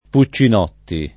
Puccinotti [ pu ©© in 0 tti ] cogn.